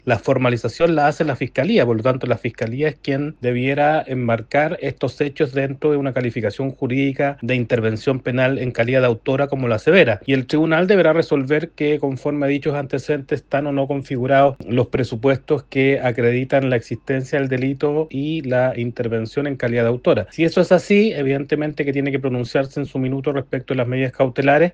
El también exfiscal de Alta Complejidad, José Antonio Villalobos, consideró que la diferencia en las calificaciones del grado de participación de Catalina Pérez no debiese afectar a la formalización que lidera el Ministerio Público.